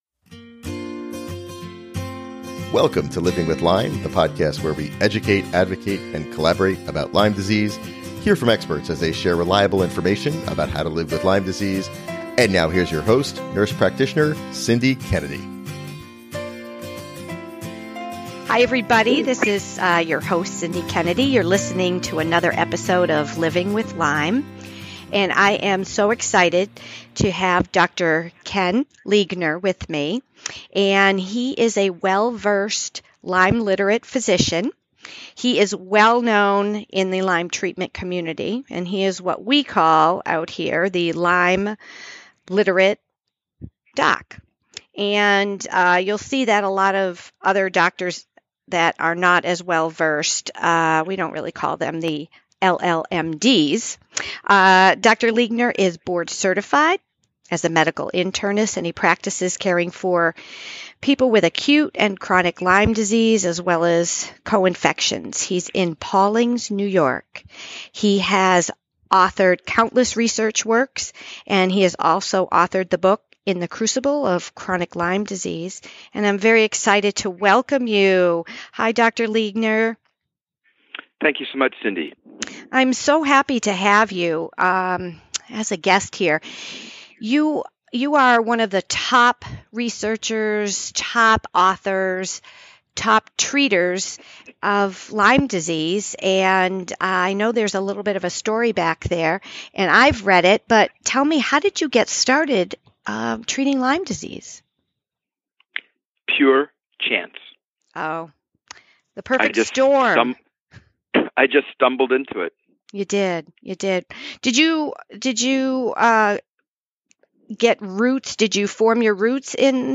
LivingwithLyme Interview